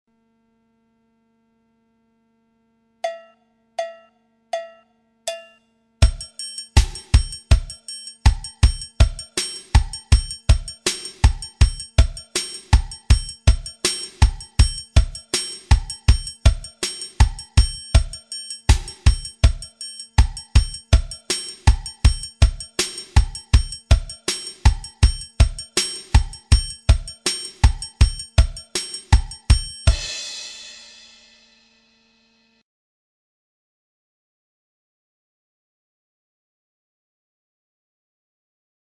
Section rythmique du xote
Les losanges du haut de la partition représentent le triangle que l'on retrouvera souvent dans les musiques du nordeste, losange noir = triangle fermé, losange blanc = triangle ouvert. La note si est un coup de caisse claire, les triangles en mi et en sol représentent des cloches en bois graves et aigues. La grosse caisse qui imite le surdo ou la zabumba est la note du bas (la ). section rytmique du xote Téléchargez ou écoutez dans le player.